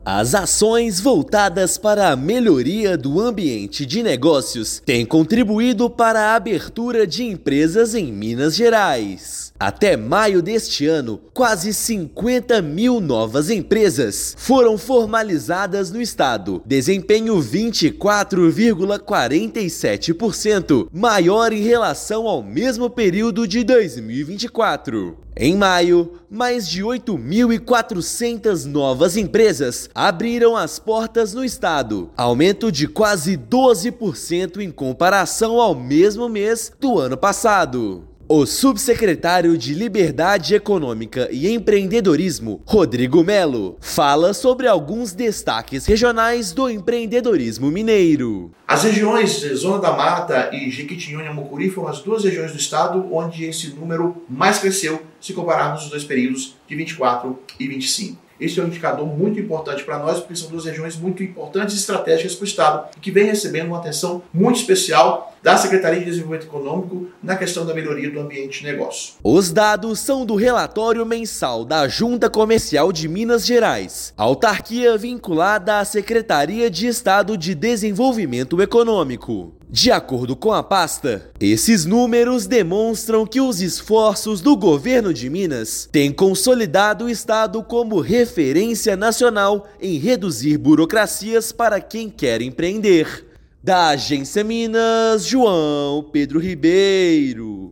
Desempenho registrado foi 24,47% maior que o número registrado de janeiro a maio de 2024 e 142% superior ao mesmo período de 2019. Ouça matéria de rádio.